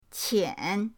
qian3.mp3